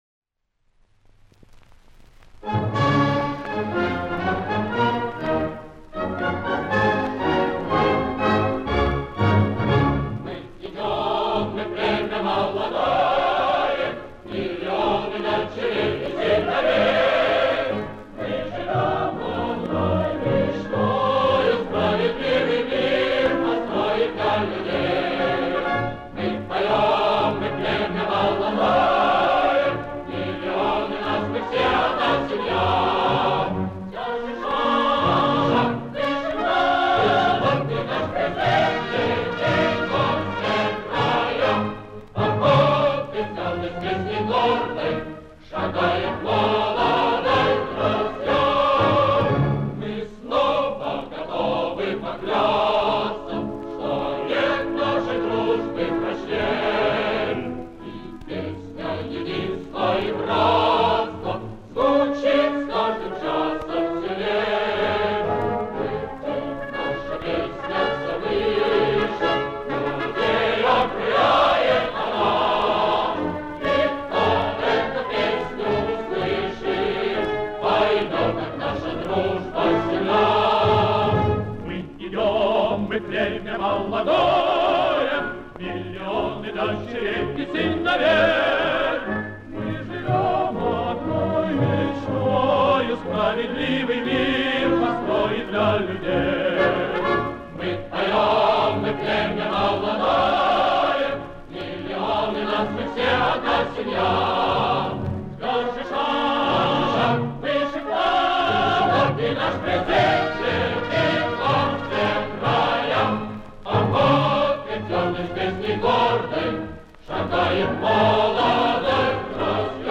Марши
Еще одна весьма неплохая фестивальная песня.